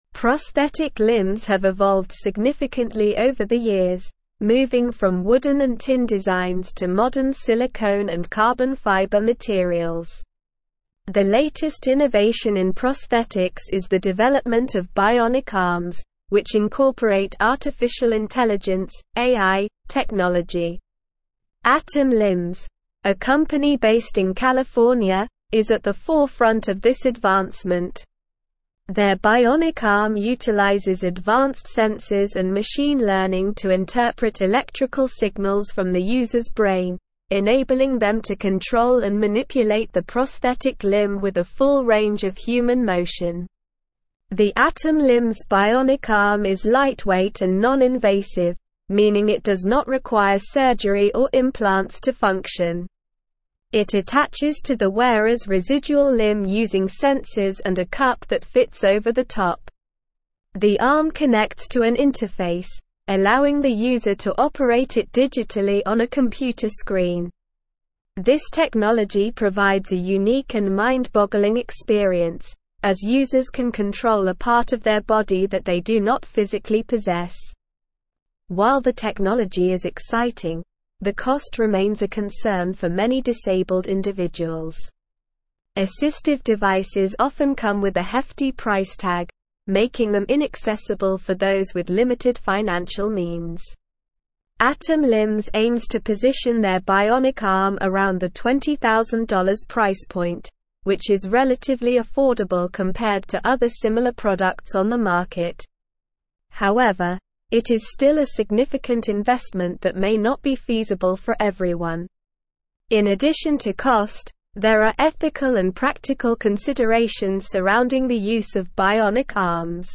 Slow
English-Newsroom-Advanced-SLOW-Reading-Next-Gen-Bionic-Arm-AI-Sensors-and-Affordable-Innovation.mp3